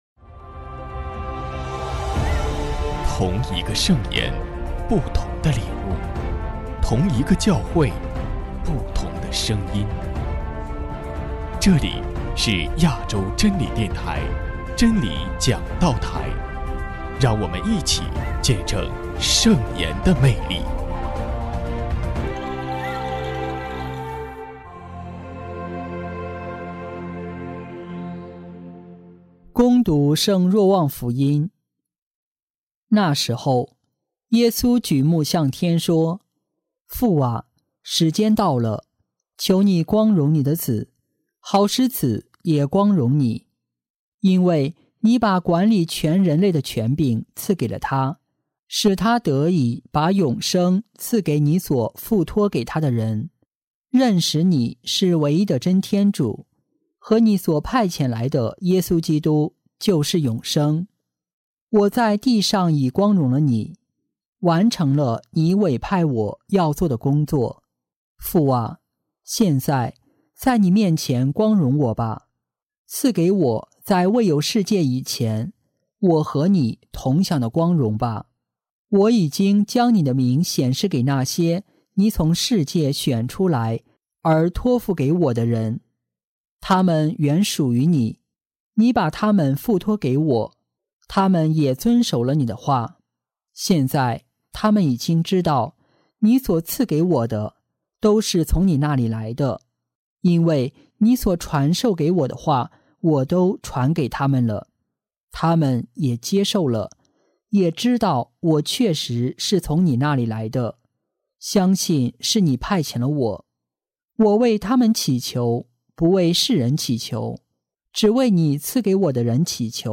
——基督的福音 证道 主 题： 宽恕是一份恩赐的高度 主内的兄弟姊妹们大家好，今天是复活期第七主日，与大家一起分享的福音主题是，“宽恕是一份恩赐的高度”。